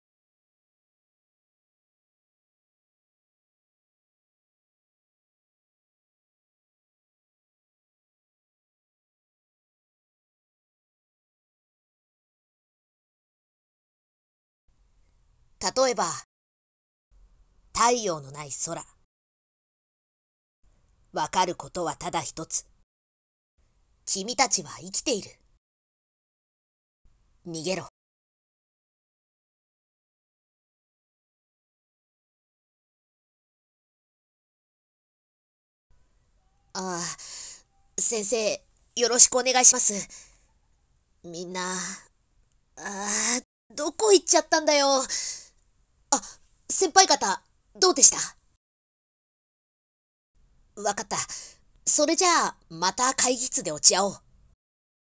】🐦‍🔥声劇セリフ nanaRepeat